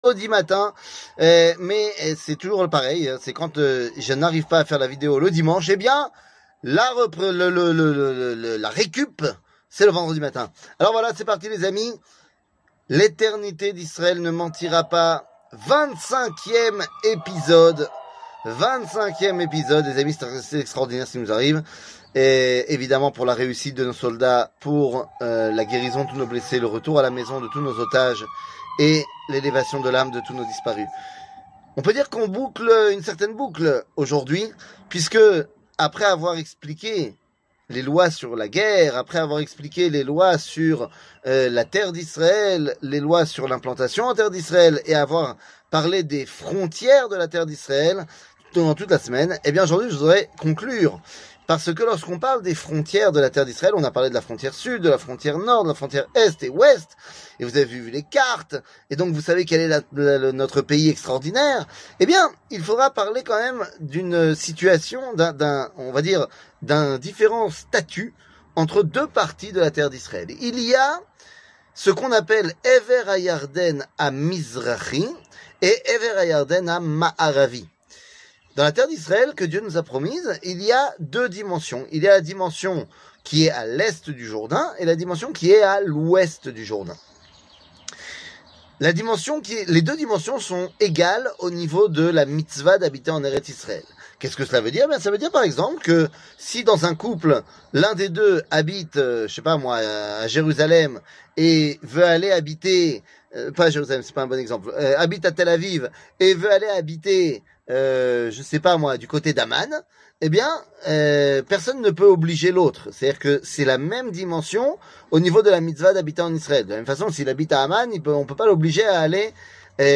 L'éternité d'Israel ne mentira pas ! 25 00:04:53 L'éternité d'Israel ne mentira pas ! 25 שיעור מ 10 נובמבר 2023 04MIN הורדה בקובץ אודיו MP3 (4.46 Mo) הורדה בקובץ וידאו MP4 (7.76 Mo) TAGS : שיעורים קצרים